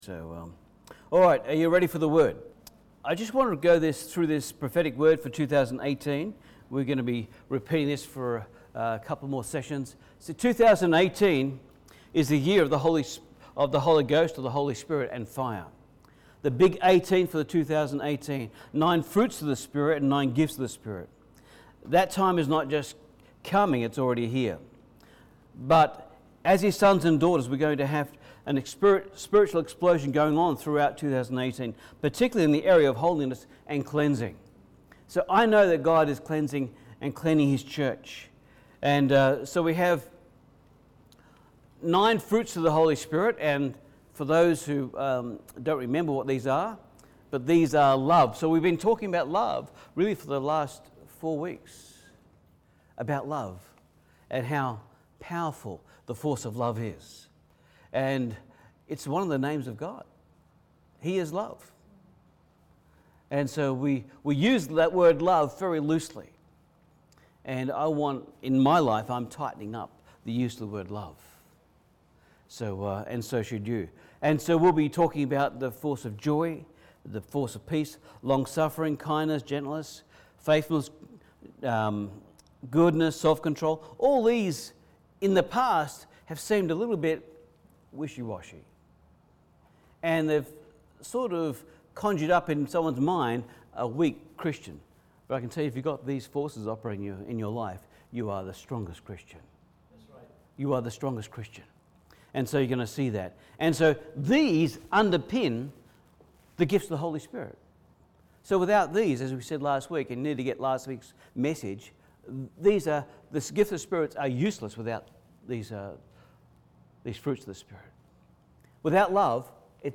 Ex 3:1-15 Service Type: Sunday Service Acts 3:16